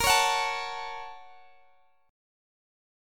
BbmM7#5 Chord
Listen to BbmM7#5 strummed